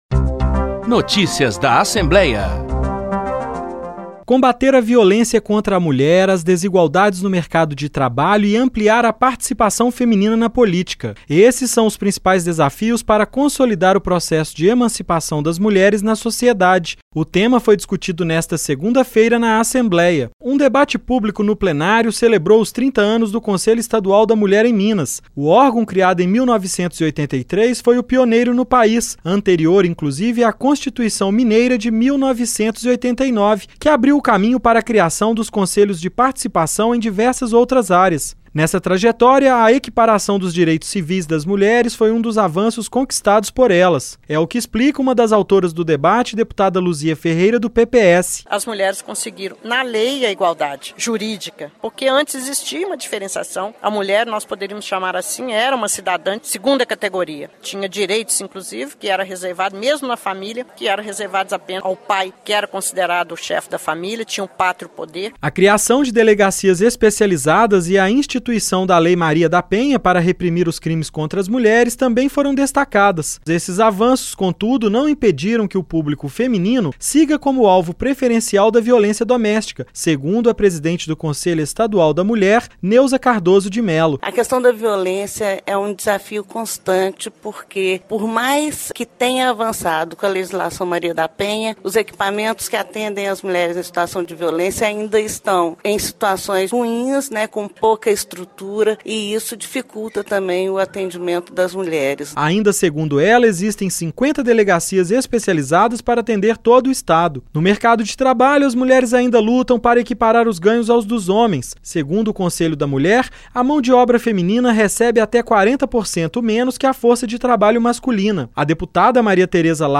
Durante evento no Plenário para celebrar os 30 anos de criação do Conselho Estadual da Mulher, deputadas defendem maior participação feminina na política.